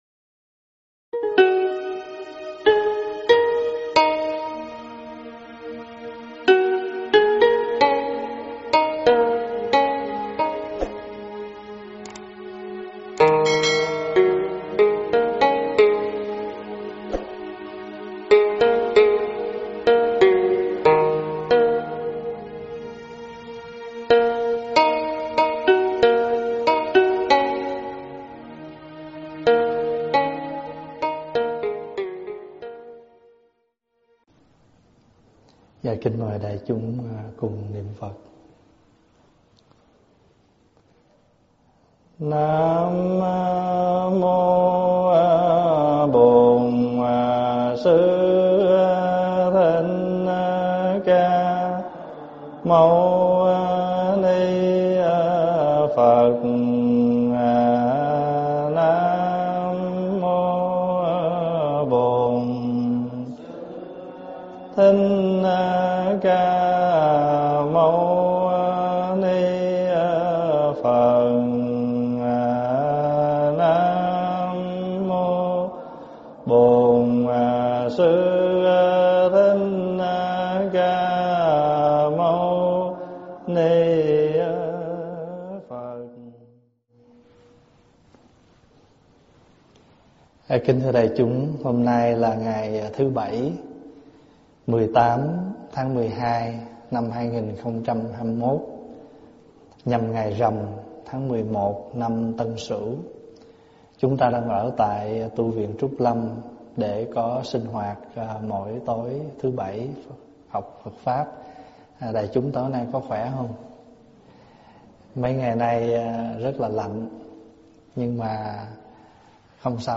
Thuyết pháp Trách Nhiệm Phật Trao 27
giảng tại Tv.Trúc Lâm